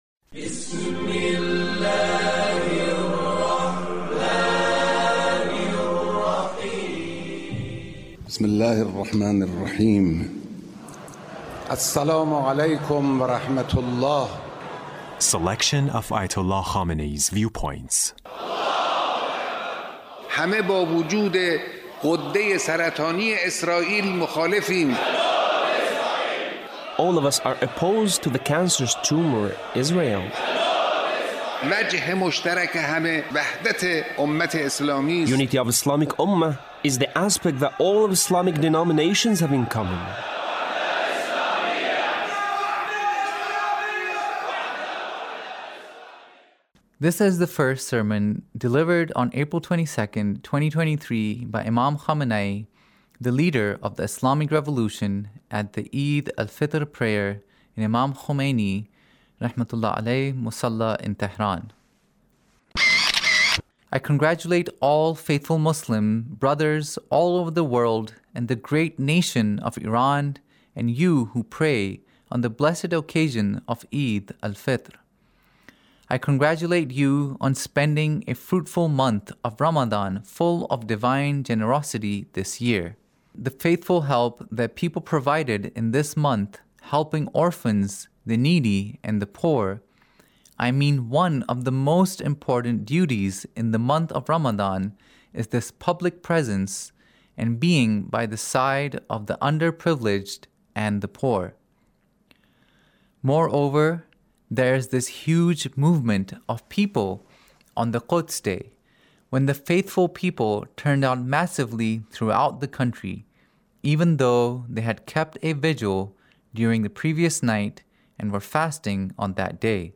Leader's Speech On Eid Al Fitr